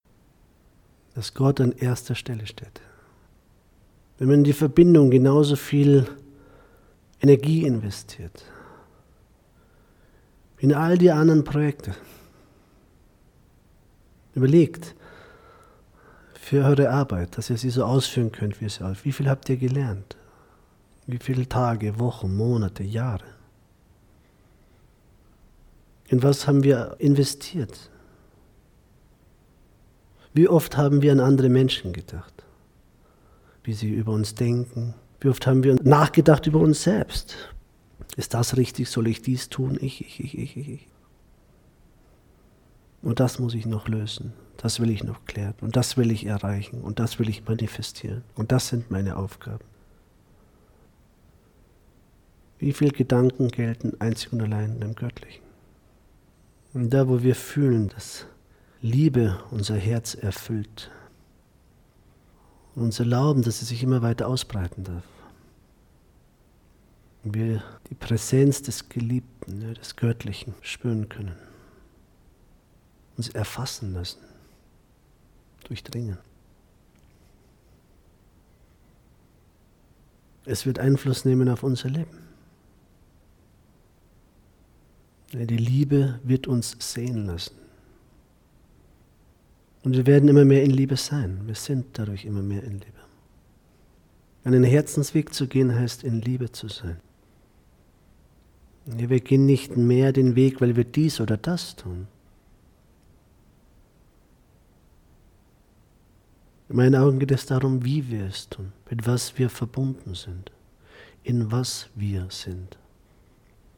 Meditation Live-Aufnahme